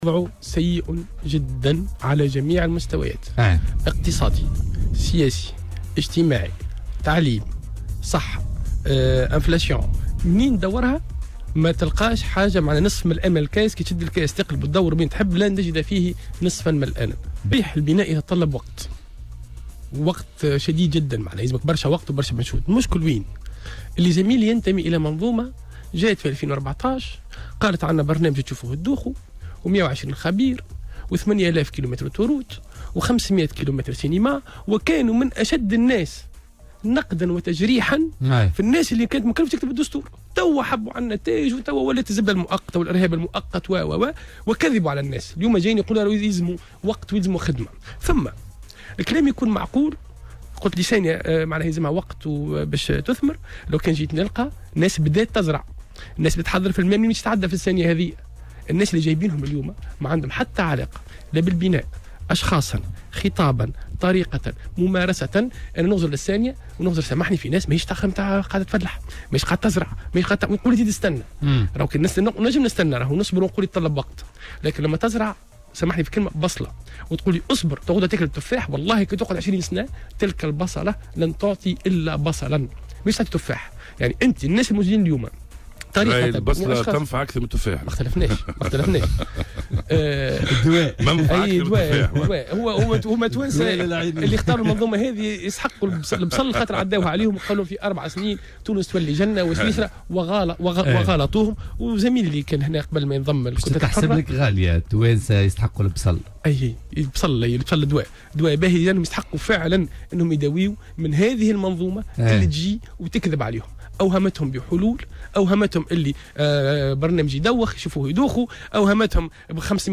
وحمّل في مداخلة له اليوم في برنامج "بوليتيكا" الحكومة والأحزاب الفائزة في الانتخابات مسؤولية تردي الأوضاع طيلة 4 سنوات، مشيرا إلى أنها قامت بالكذب على الناخبين وأوهمتهم بقدرتها على إخراج البلاد من أزمتها وامتلاكها لبرنامج إصلاح.